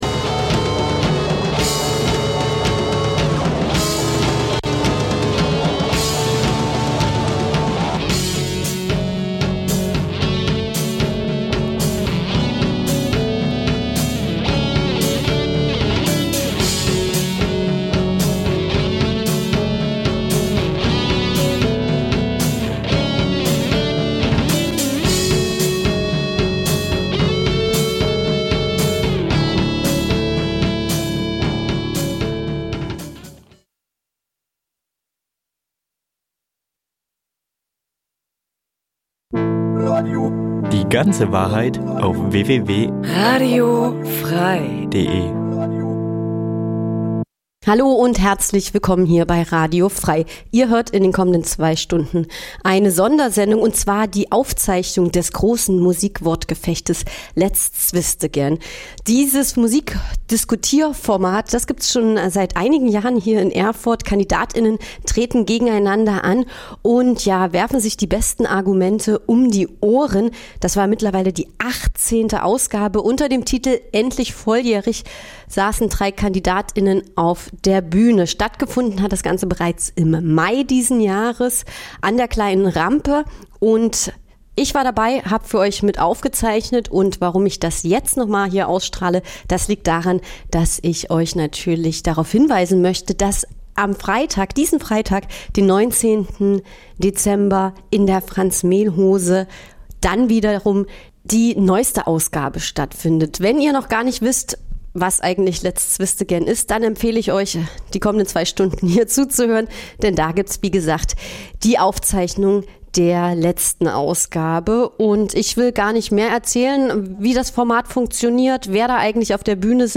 Aufzeichnung vom 24. Mai 2025 in der Kleinen Rampe Erfurt.